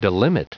Prononciation du mot delimit en anglais (fichier audio)